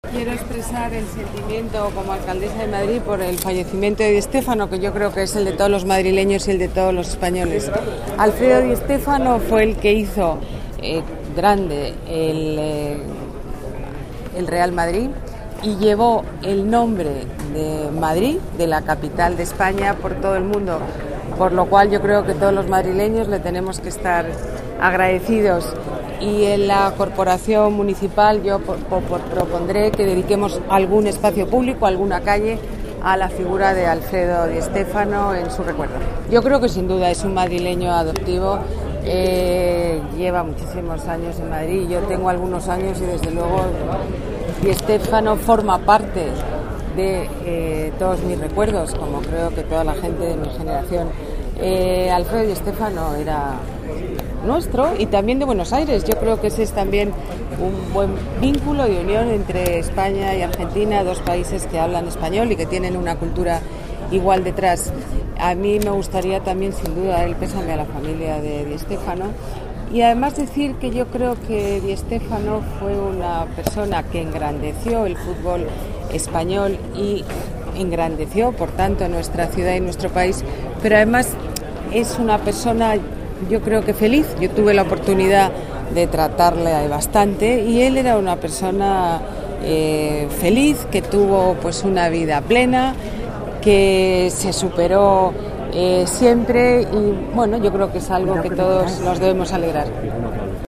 Nueva ventana:Declaraciones alcaldesa Ana Botella: espacio público para DiStefano